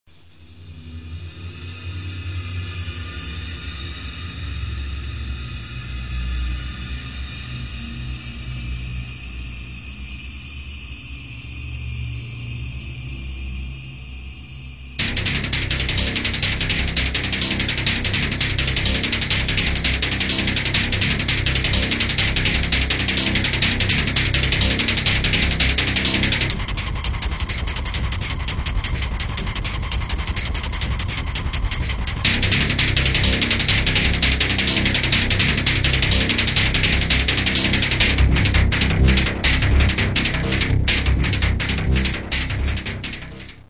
Каталог -> Классическая -> Нео, модерн, авангард